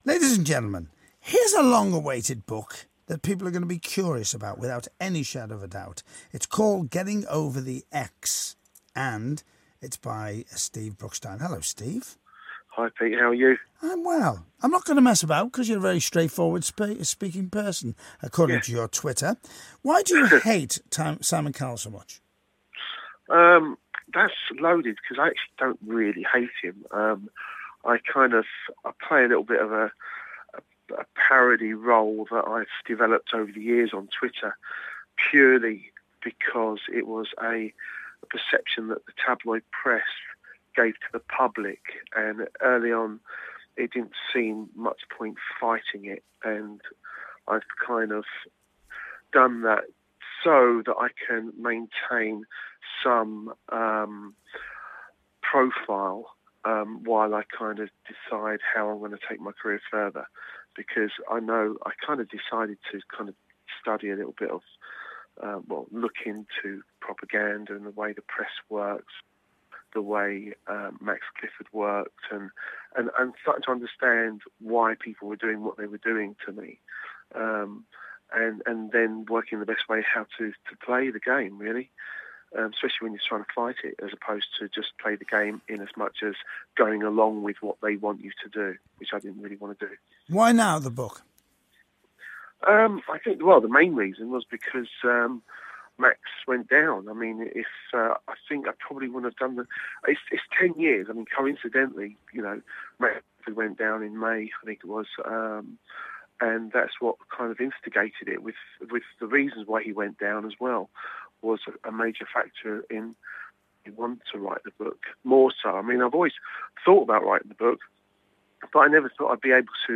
The first winner of the x-factor joined me to discuss his new book, and had a surprising revelation about who wanted to pen it!...